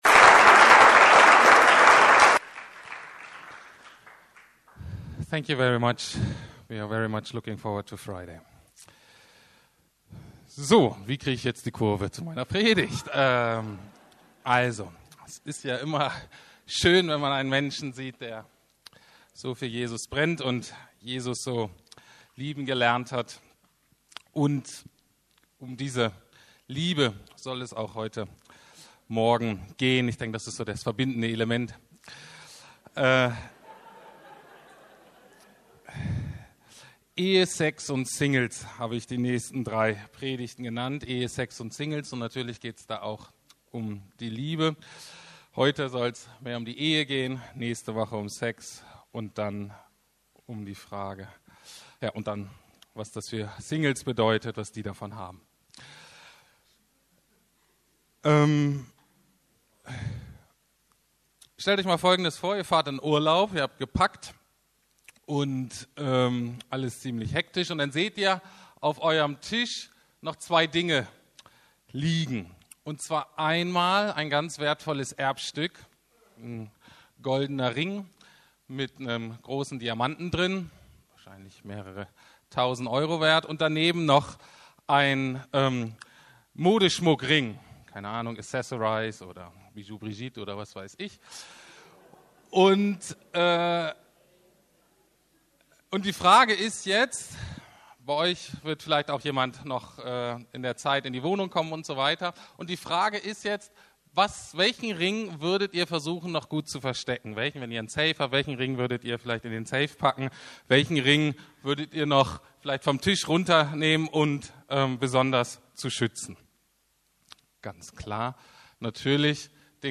Sex, Singels und Verheiratete - und was sie mit Gott zu tun haben - Teil 1/3 ~ Predigten der LUKAS GEMEINDE Podcast